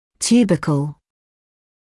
[‘t(j)uːbəkl][‘т(й)уːбэкл]бугорок